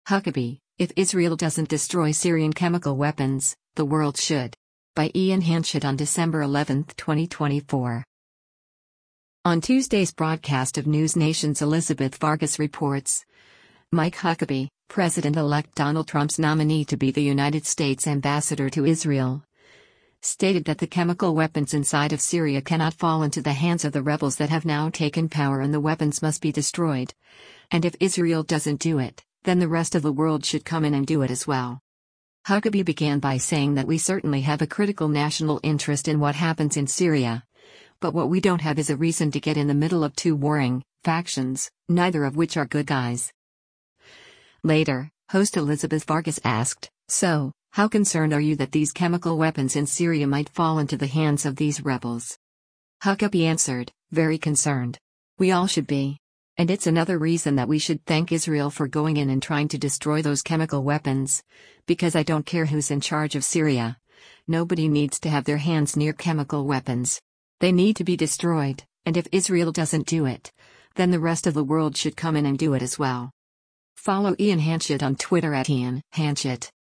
On Tuesday’s broadcast of NewsNation’s “Elizabeth Vargas Reports,” Mike Huckabee, President-Elect Donald Trump’s nominee to be the United States’ Ambassador to Israel, stated that the chemical weapons inside of Syria cannot fall into the hands of the rebels that have now taken power and the weapons must “be destroyed, and if Israel doesn’t do it, then the rest of the world should come in and do it as well.”
Later, host Elizabeth Vargas asked, “So, how concerned are you that these chemical weapons in Syria might fall into the hands of these rebels?”